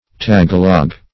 tagalog - definition of tagalog - synonyms, pronunciation, spelling from Free Dictionary
Tagalog \Ta*ga"log\, n.
tagalog.mp3